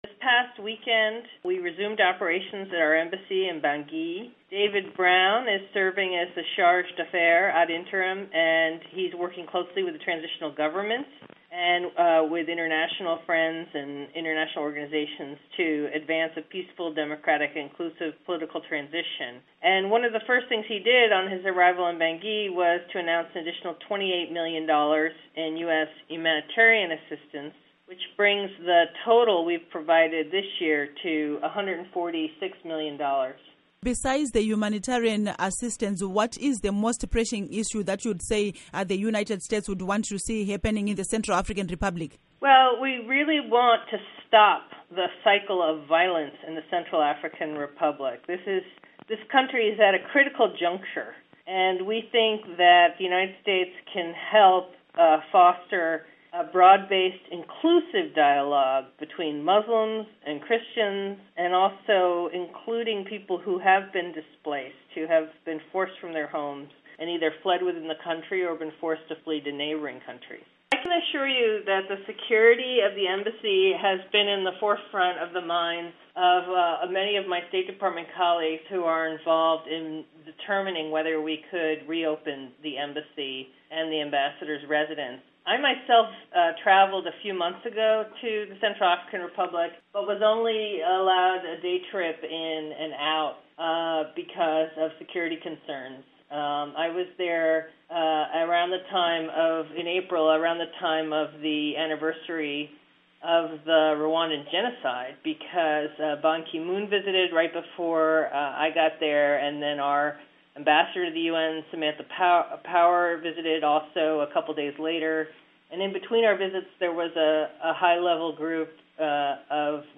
Interview With Ane Richard, US Assistant Secretary of State for Population, Refugees and Migration